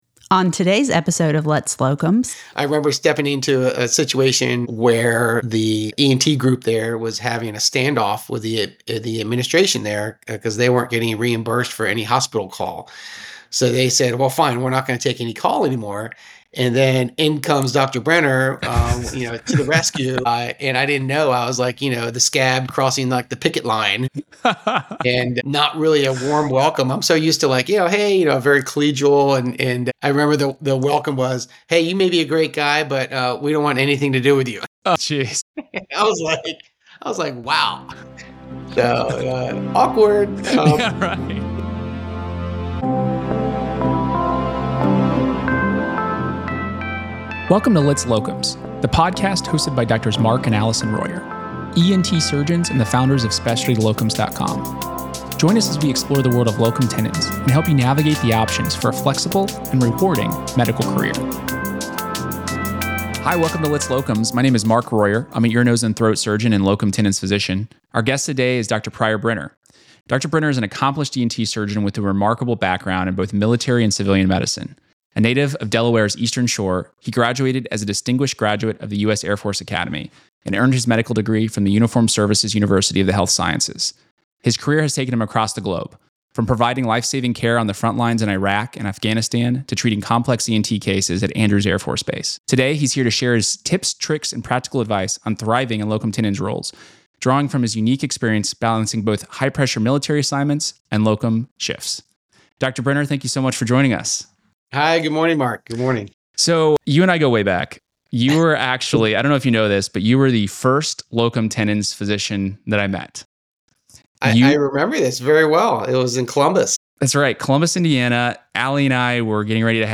The series guides medical professionals through the intricacies of locum tenens roles, featuring interviews with experienced physicians and exploring the pros and cons of this career path.